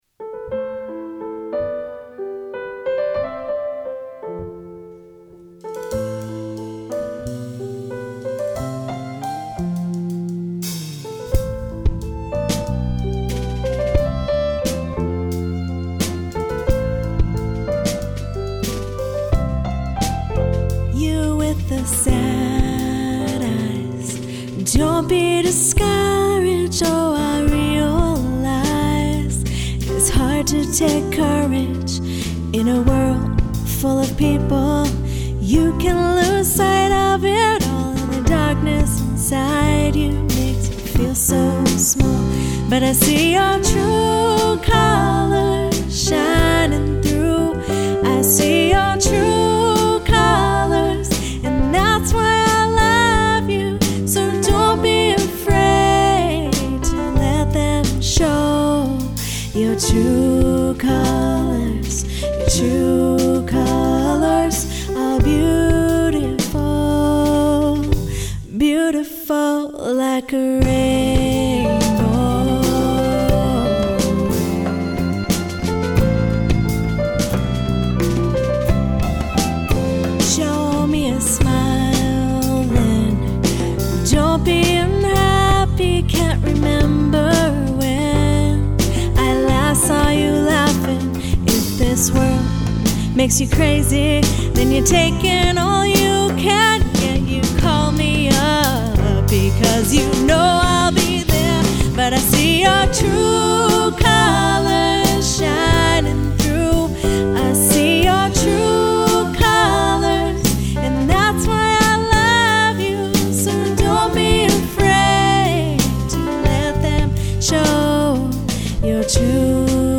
Fresh arrangements of jazz and pop standards and originals